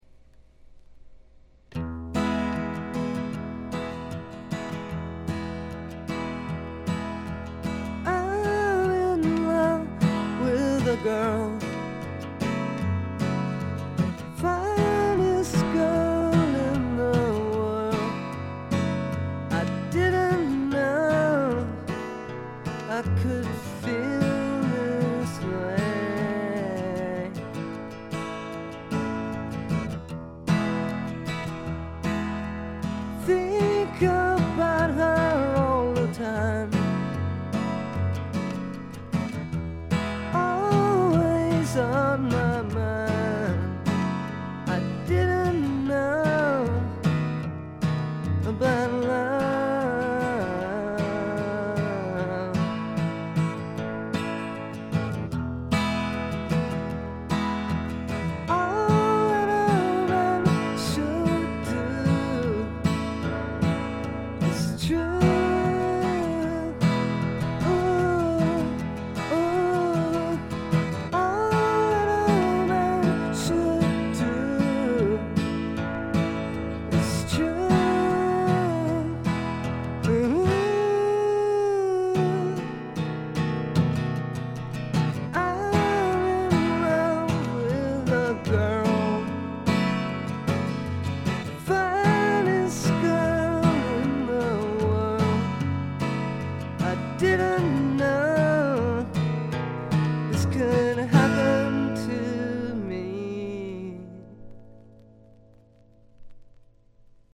散発的なプツ音が3ヶ所ほど（ほとんど気付かないレベル）。
試聴曲は現品からの取り込み音源です。